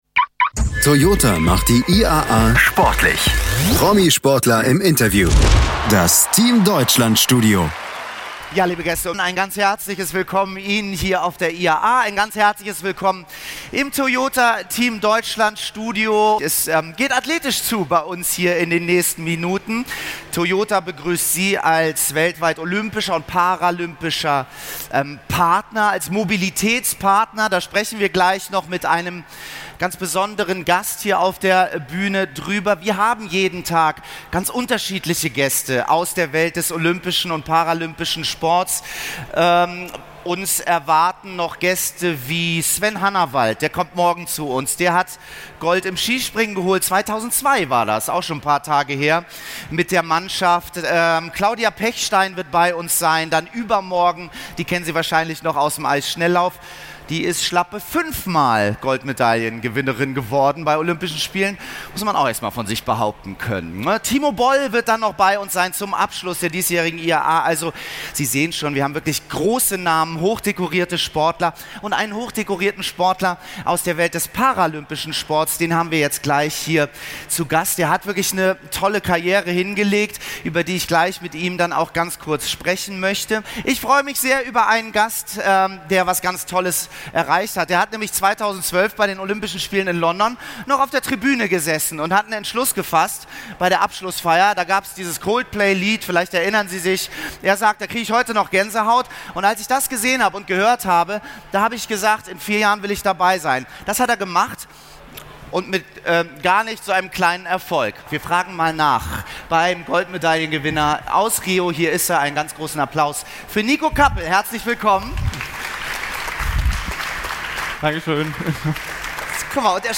Niko Kappel im Interview ~ Behindertensport Podcast
In dieser Ausgabe ist Niko Kappel im Team Deutschland Studio zu Gast. Er ist Olympiasieger im Kugelstoßen.
niko-kappel-im-interview.mp3